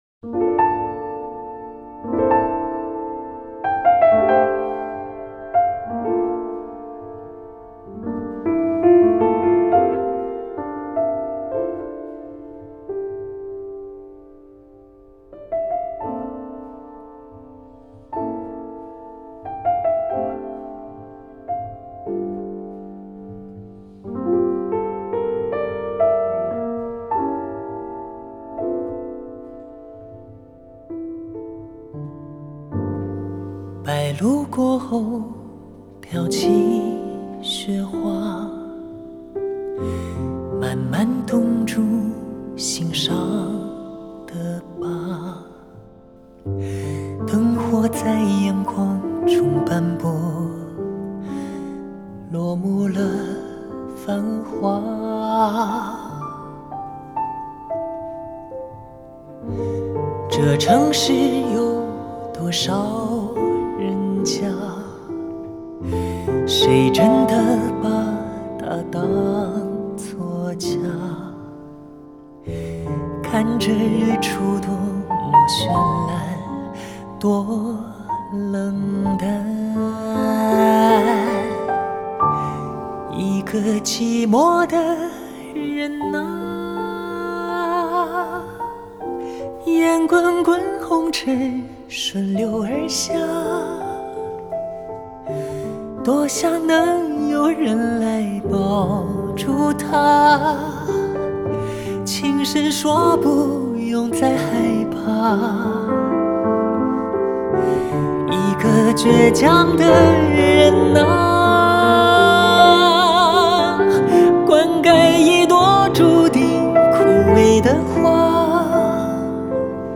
Ps：在线试听为压缩音质节选，体验无损音质请下载完整版
钢琴
小号
鼓
贝斯
弦乐团